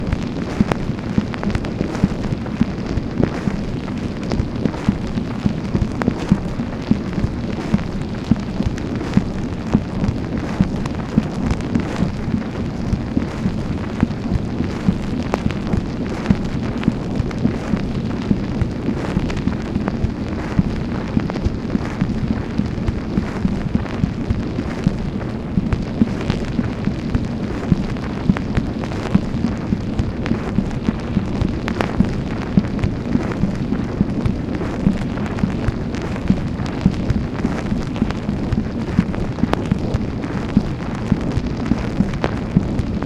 MACHINE NOISE, July 23, 1965
Secret White House Tapes | Lyndon B. Johnson Presidency